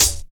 Hat_1_(Prog_Stepz)_(JW2).wav